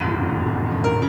Keys_02.wav